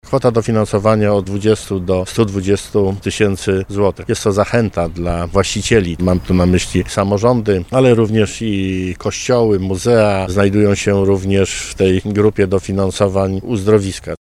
Cezary Przybylski, Marszałek Województwa Dolnośląskiego mówi, że grono wnioskodawców jest mocno zróżnicowane.